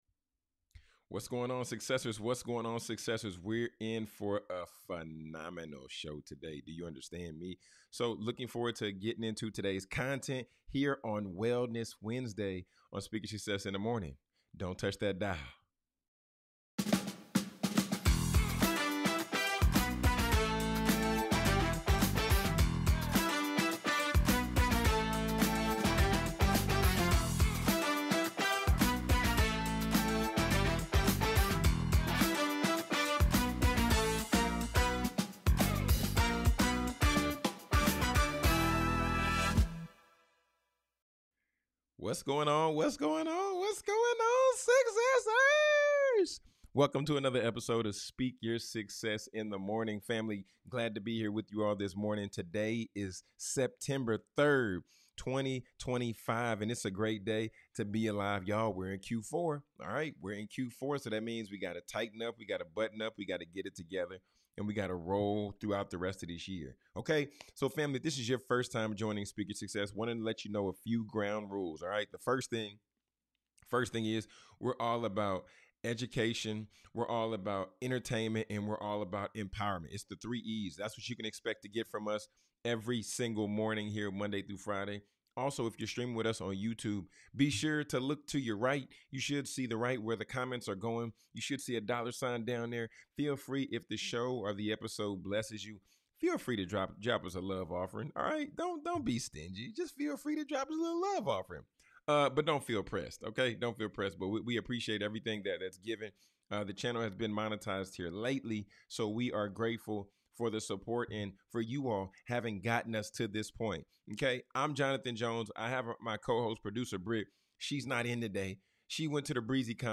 Guided Meditation - Wellness Wednesday - SYSM - #431